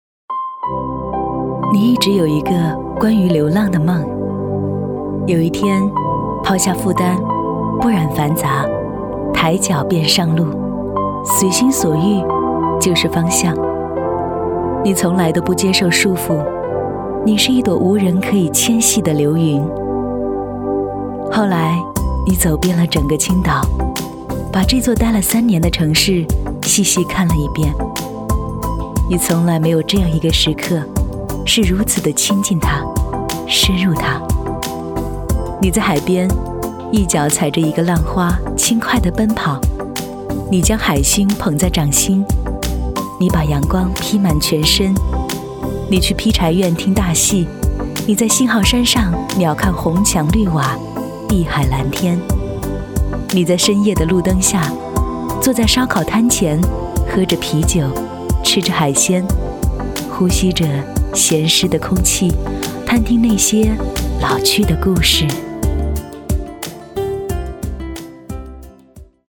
女15号--城市宣传-清新自然-青岛.mp3